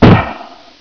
sound / weapons / grapple